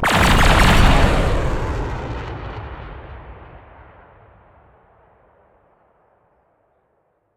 ships / combat / weapons / salvotorpa.ogg